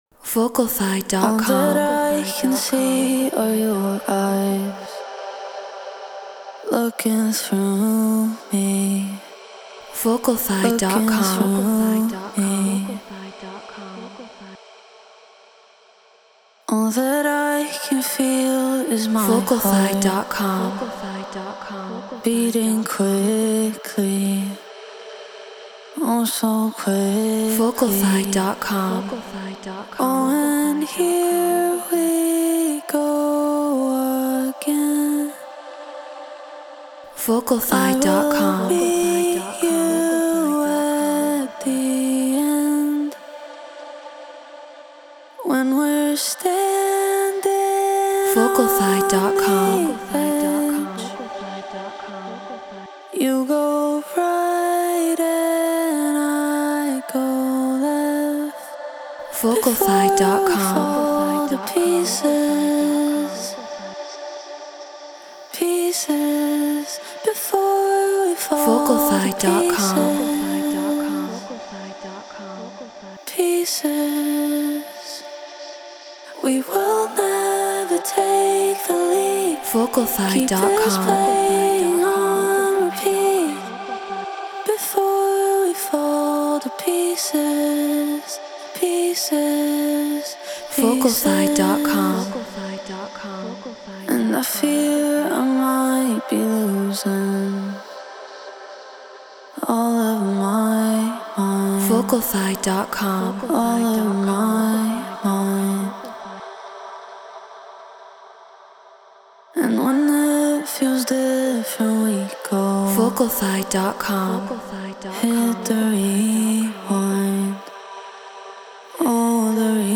House 124 BPM Dmin
Shure SM7B Apollo Solo Logic Pro Treated Room